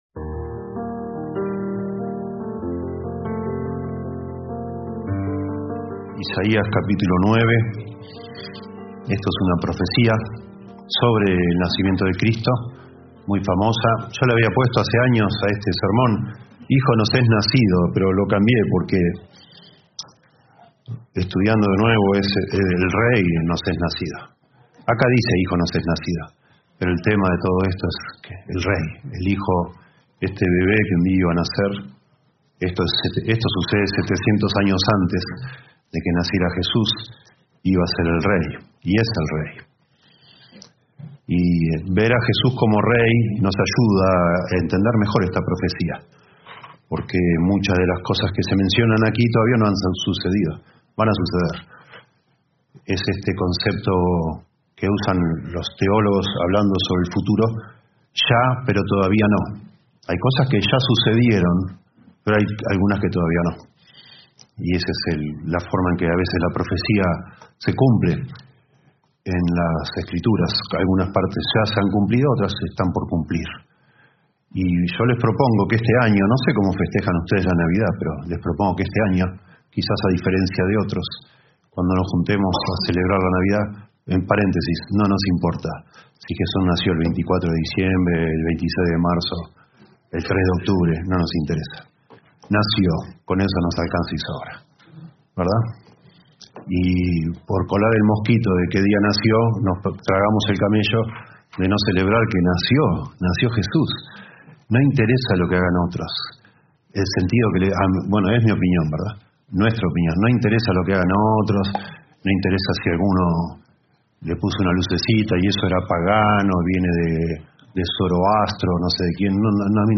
Reina-Valera 1960 (RVR1960) Video del Sermón Audio del Sermón Descargar audio Temas: Navidad